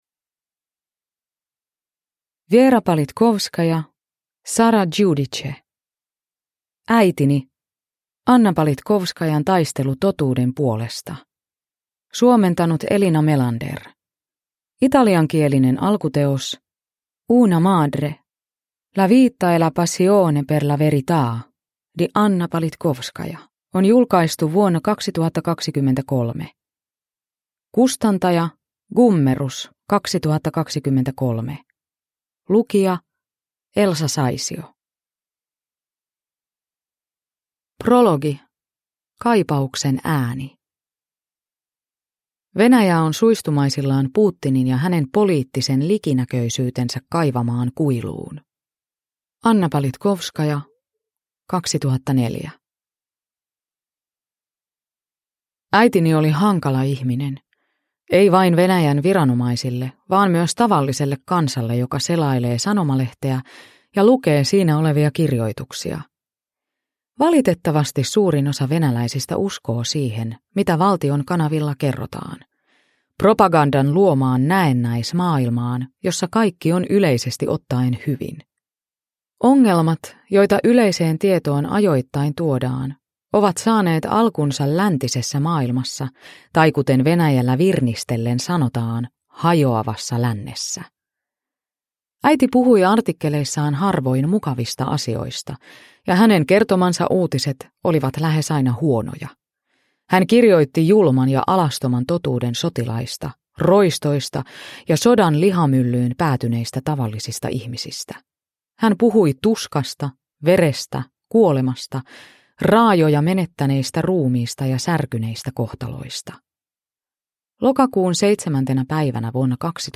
Äitini – Ljudbok – Laddas ner